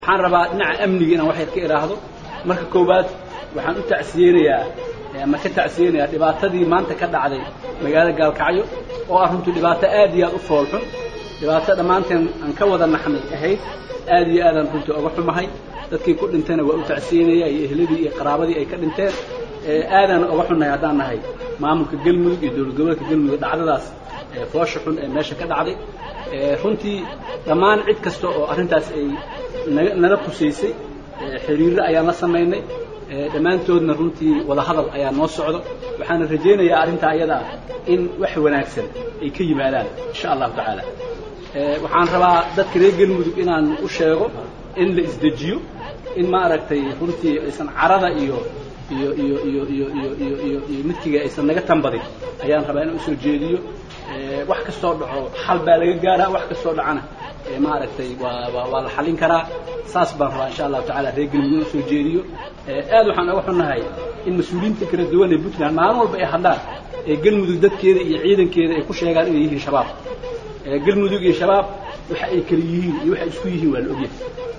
29 sept 2016 (Puntlandes) Madaxweynaha maamulka ka kooban gobol iyo barka ee Galmudug, oo la hadlaya saxaafadda shal goor uu kasoo degay Cadaado ayaa waxaa uu sheegay in loo baahan yahay in ay shacxabku isdajiyaan.